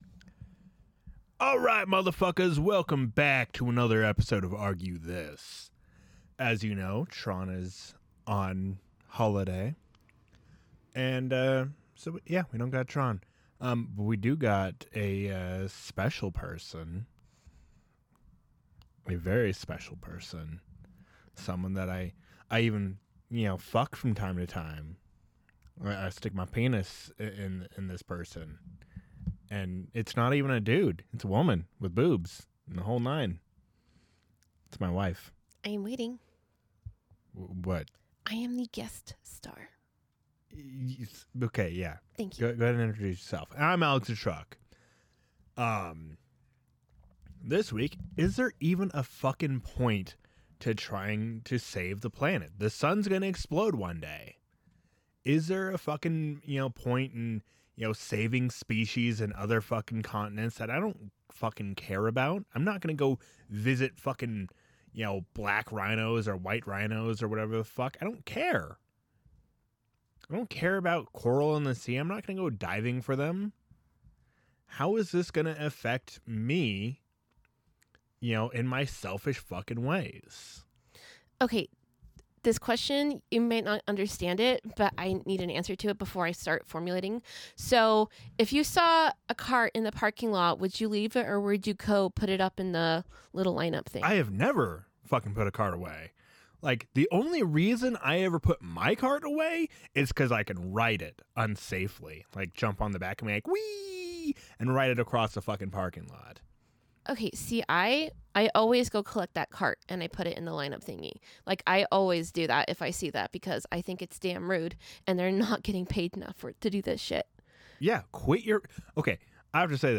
[Explicit Language] Why should we care about the planet when the sun will explode and destroy life as we know it. Is nuclear power better than solar and wind.